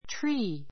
tree 小 A1 tríː ト リ ー 名詞 複 trees tríːz ト リ ー ズ (立ち)木 , 樹木 wood ⦣ 幹や枝を備えた立ち木をいう. climb (up) a tree climb ( up ) a tree 木に登る There is a big oak tree in our garden.